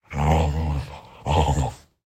Minecraft Version Minecraft Version latest Latest Release | Latest Snapshot latest / assets / minecraft / sounds / mob / wolf / big / whine.ogg Compare With Compare With Latest Release | Latest Snapshot
whine.ogg